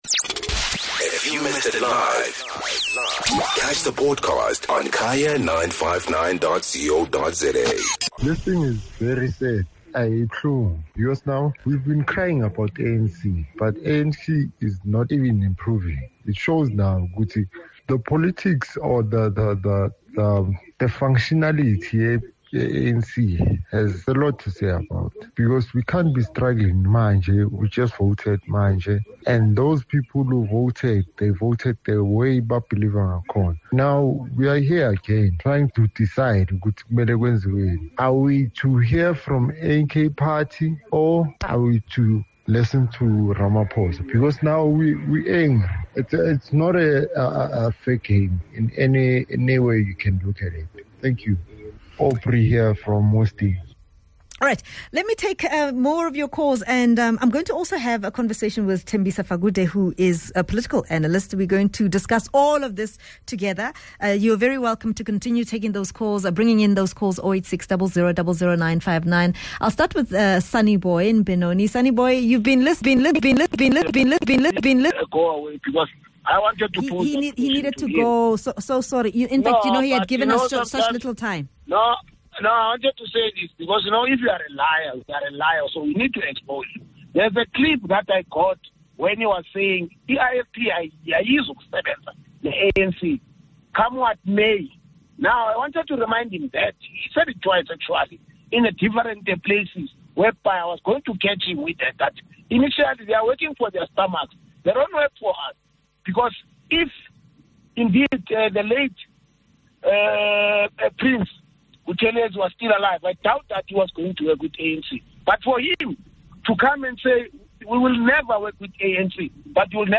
Political Analyst